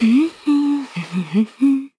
Xerah-Vox_Hum.wav